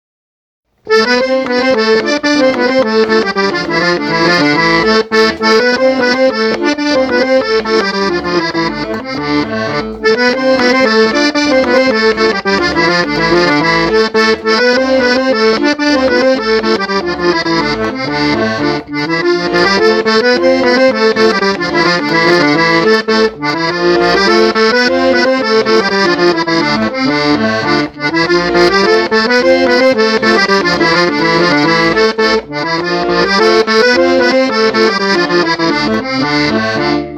Suche den Namen des Liedes der irischen (oder evtl. schottischen?) Folkmelodie gemäß Anhang.
EDIT: Der Vater eines Cousins, der selbst auch Musiker ist, wusste es - La Rotta, ein mittelalterlicher Tanz aus dem 14. Jahrhundert.